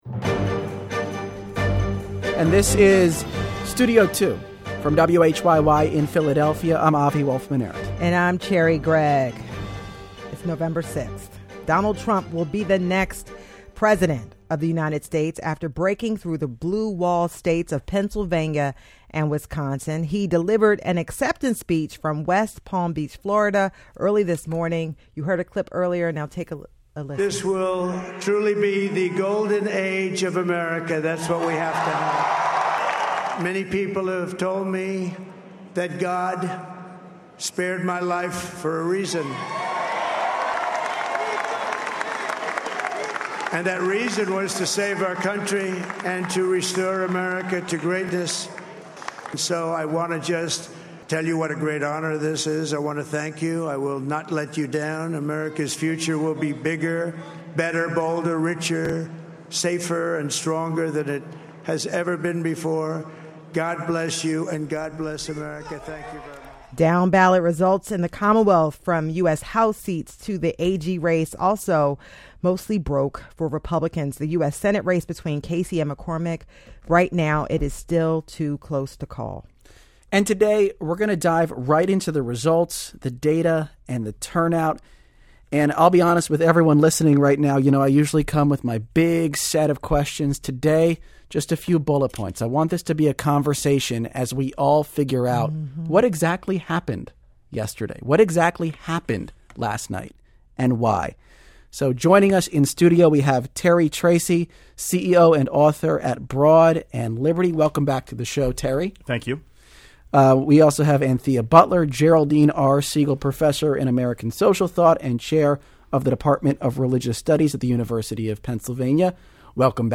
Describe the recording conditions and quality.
It was musical chairs at the Mayoral Forum in Mt. Airy on Wednesday night with candidates coming and going, and two never showing up.